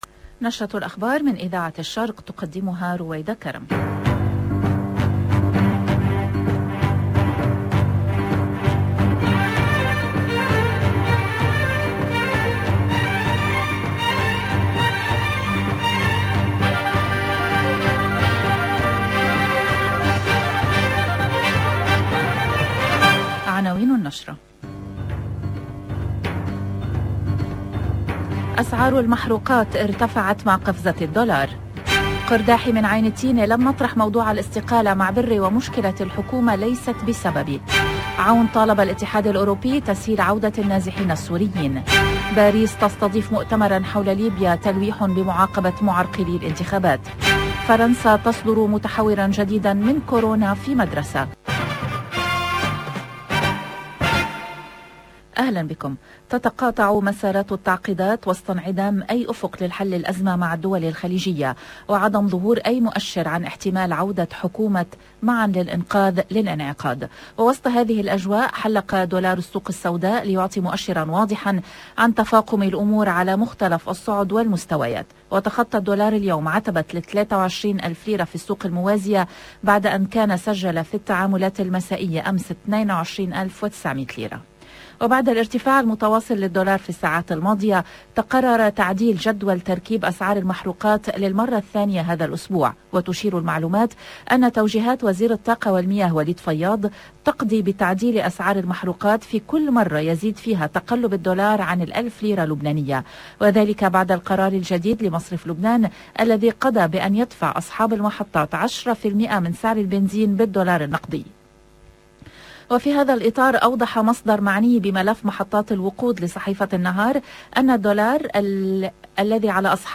LE JOURNAL DU LIBAN EN LANGUE ARABE DE LA MI-JOURNEE DU 12/11/21